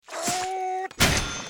Squidward slams cash register